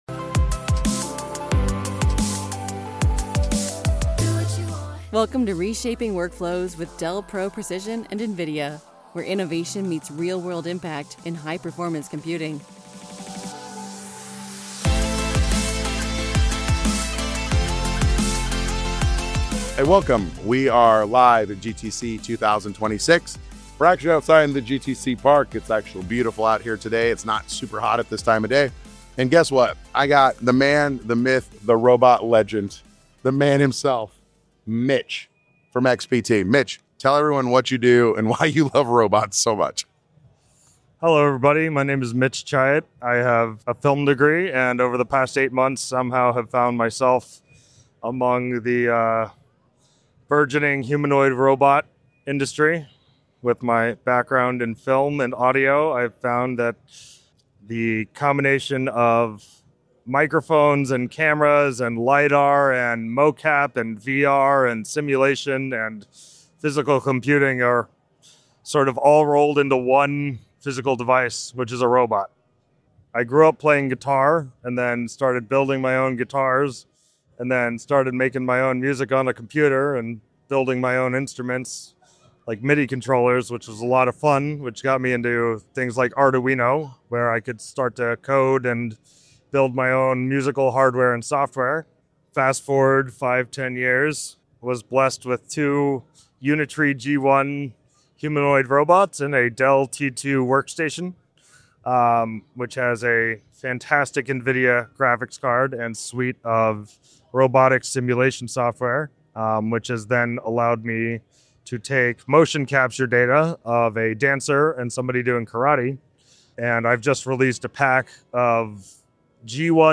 Live from GTC